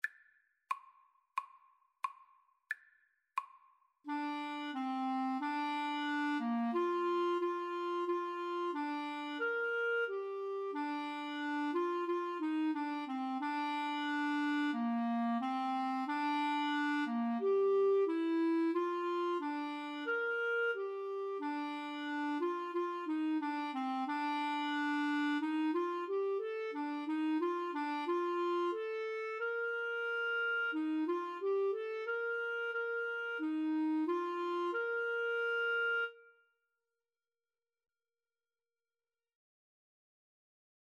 Bb major (Sounding Pitch) C major (Clarinet in Bb) (View more Bb major Music for Clarinet Duet )
Clarinet Duet  (View more Easy Clarinet Duet Music)